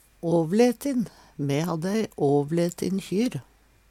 avletin - Numedalsmål (en-US)